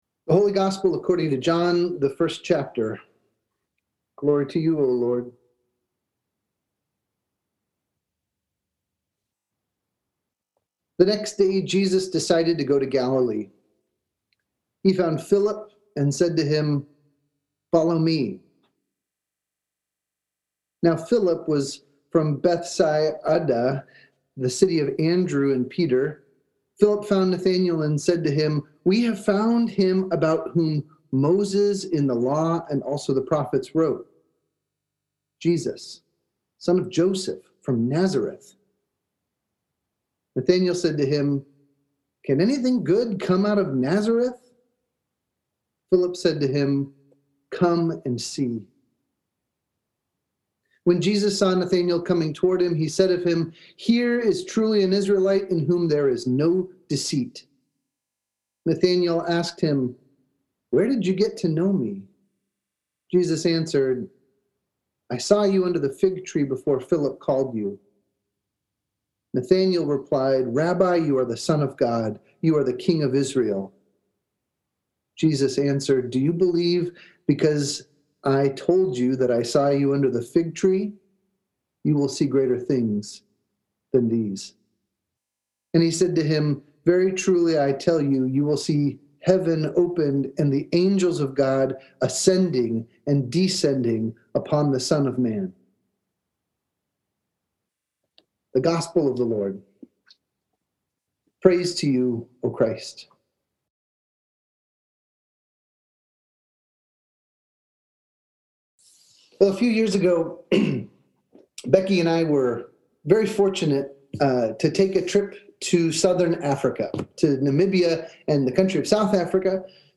Sermons | Shepherd of the Valley Lutheran Church